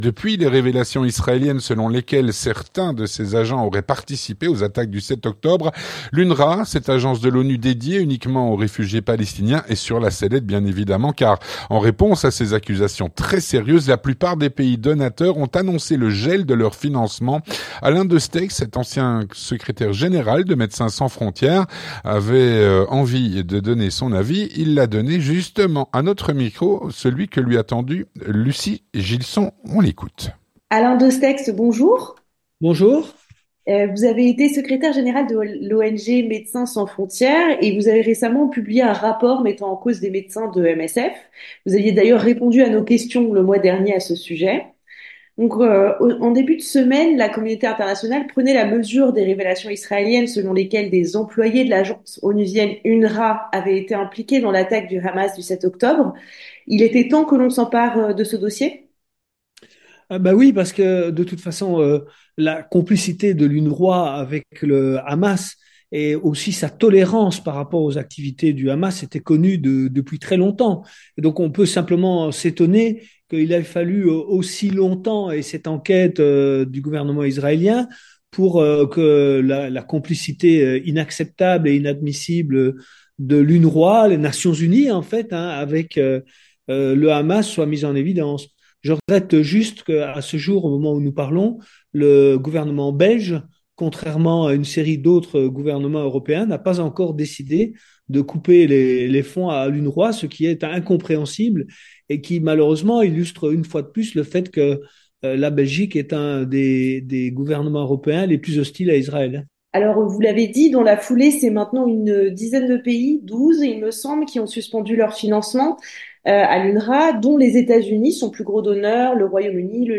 L'entretien du 18H - Gel du financement de l'UNWRA en raison de son implication dans les massacres du07 octobre. Avec Alain Destexhe (31/01/2024)